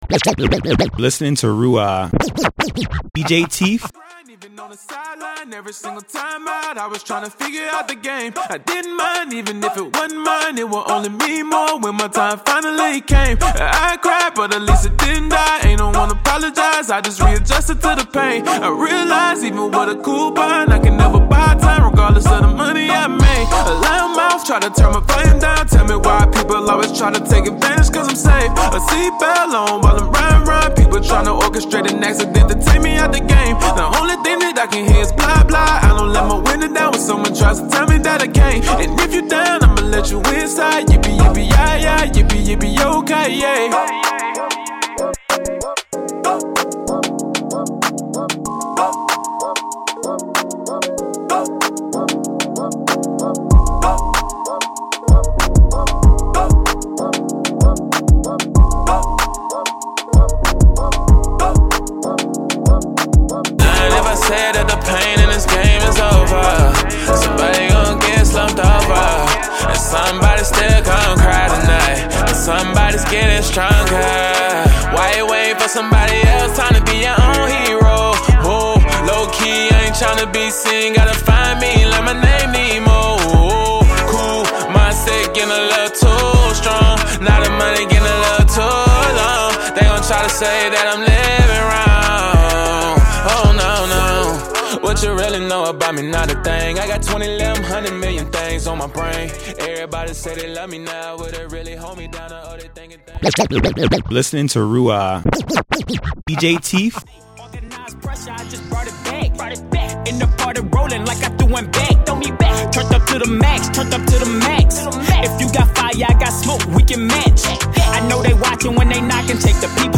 Hip-Hop Mix Demo #2